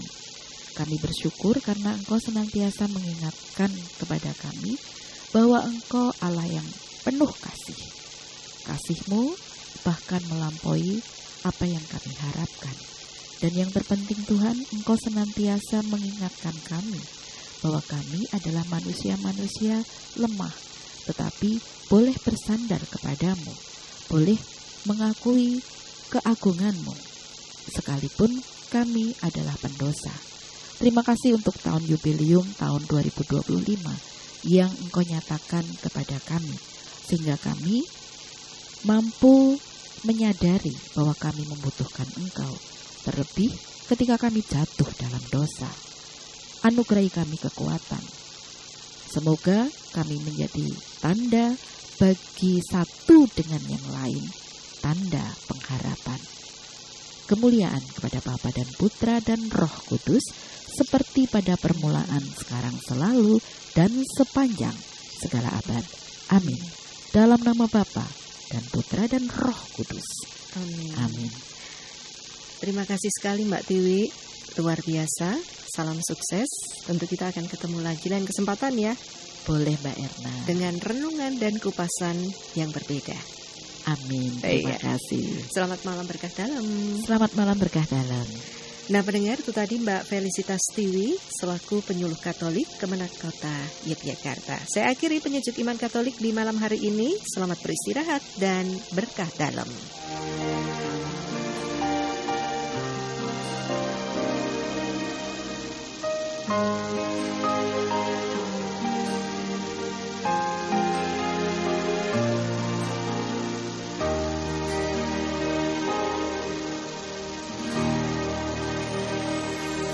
Rekaman Siaran Pro 1 FM 91.1Mhz RRI Yogyakarta
SANDIWARA RADIO